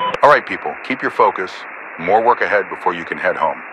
Radio-commandObjectivesManyLeft5.ogg